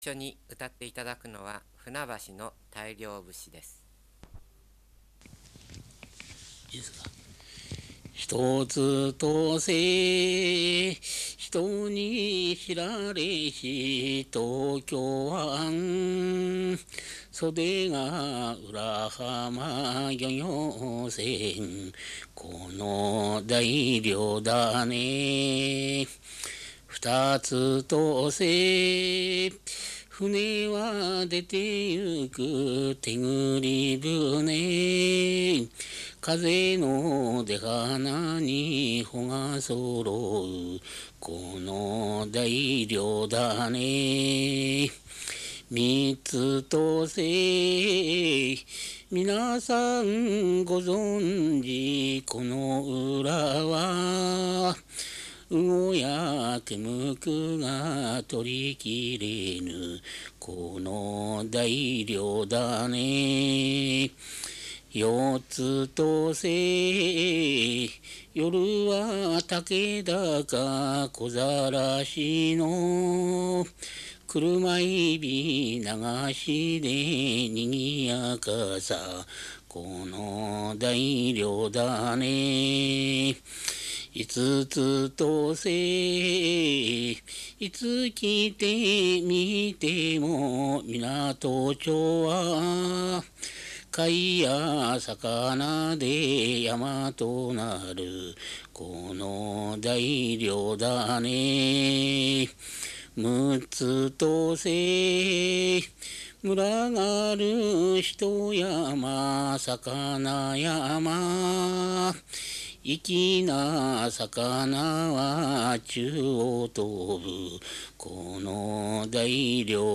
2 2 16 船橋市 　 湊町
船橋の大漁節 座興歌